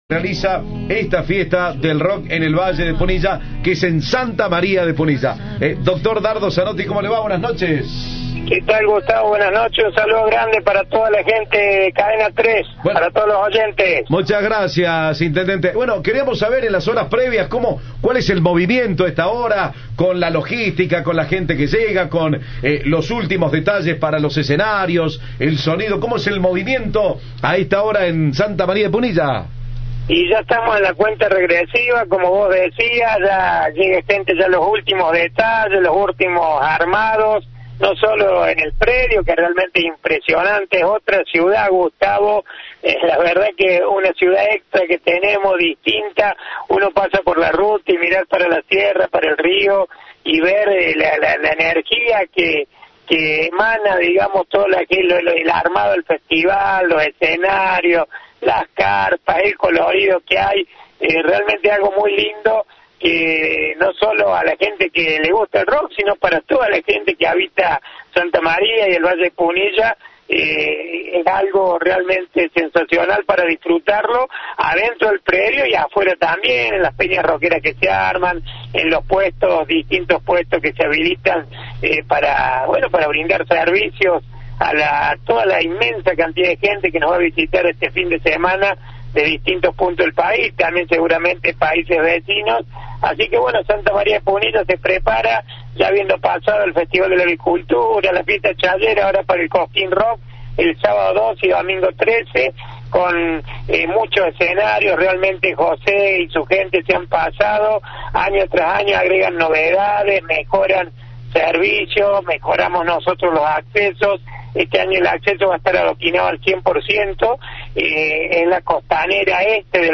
El intendente Dardo Zanotti habló con Cadena 3 sobre los preparativos de la localidad para el gran festival de música. Repasó las mejoras para este año y detalló que hay cuatro accesos.
Entrevista